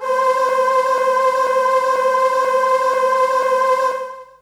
55be-syn15-c4.wav